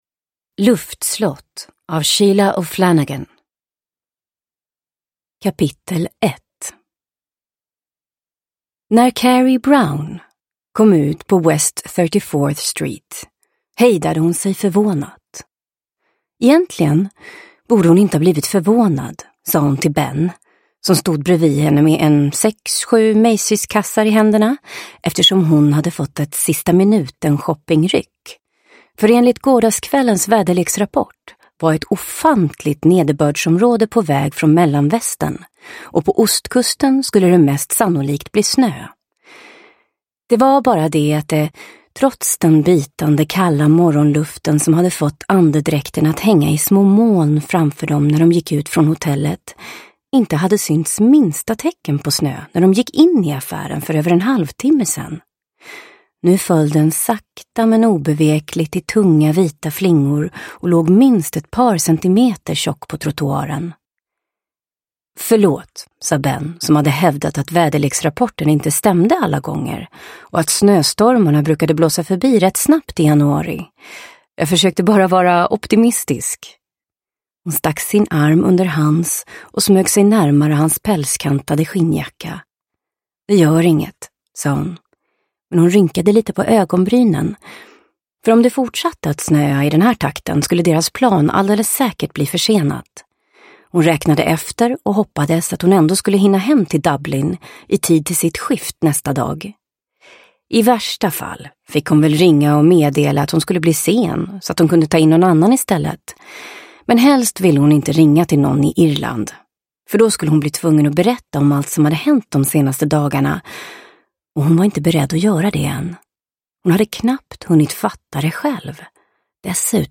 Luftslott – Ljudbok – Laddas ner